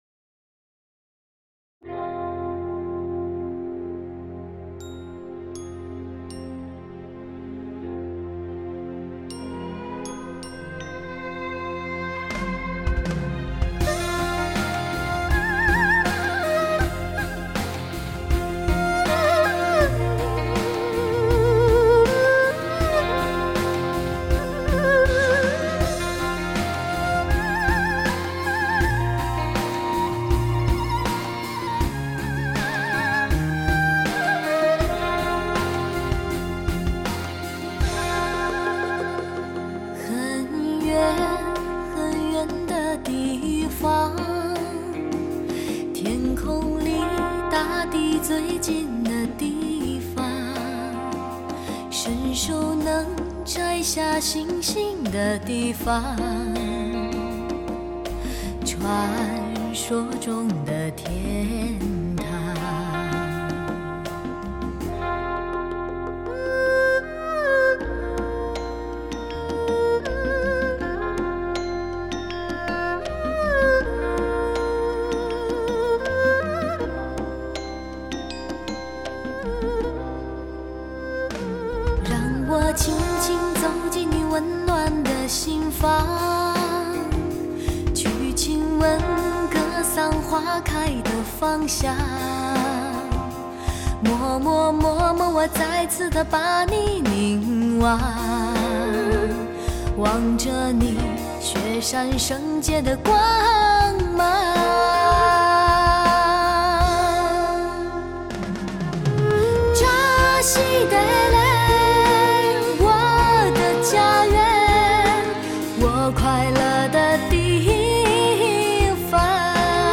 空灵纯净的嗓音，遥远而神秘的韵味让你身醉、心醉、神醉、魂醉。
环绕音效，唯一指定专业试机碟，360度旋转立体声效果，超时空，三维立体，空间的非凡体验，还原最真实的现场音乐效果。